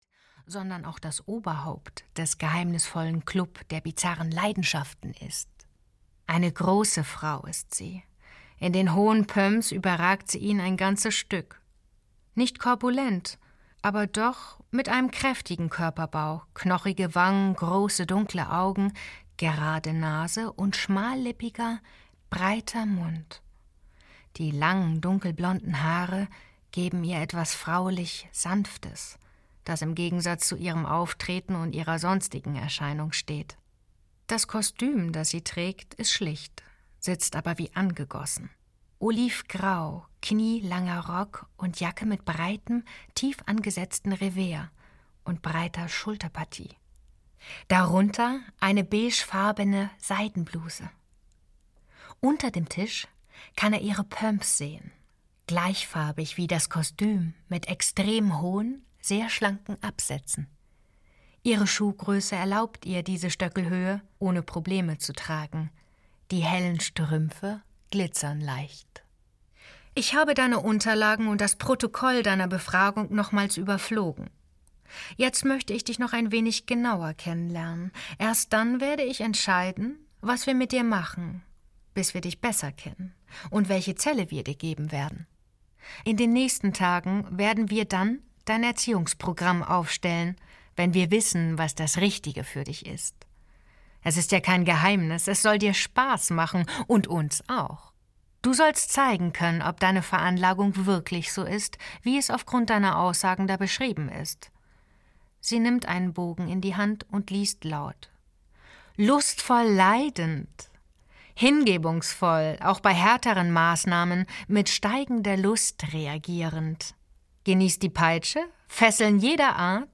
Im Arrest der Stiefelfrauen - F.M. Gernot - Hörbuch